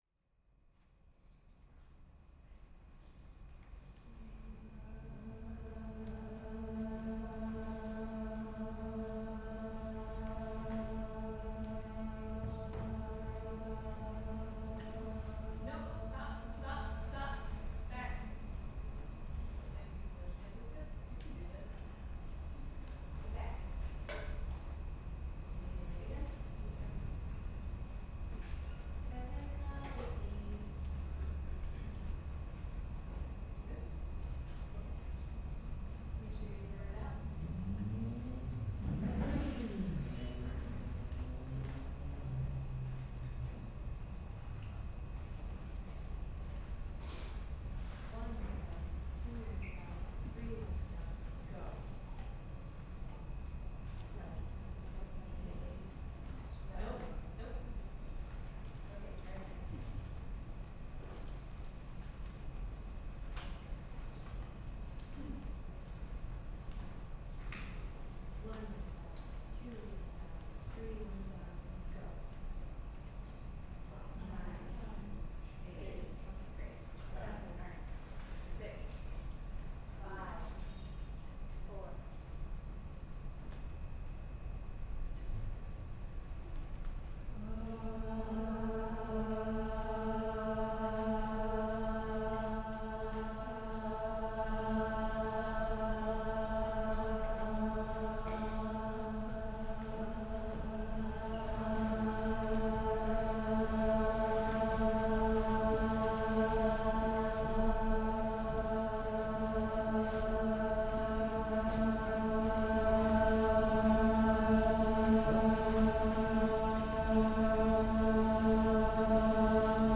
A boundary-blurring, ever-shifting choral performance that seeks to introduce a heightened sense of engagement and symbiosis.
at Roulette 2025
In it, a curation of vocalists from diverse backgrounds perform an unrehearsed a’capella piece in which musical cues and venue-specific movement directions are fed to them through earphones, physically foregrounding specific singers or creating positional unity at different points in the piece.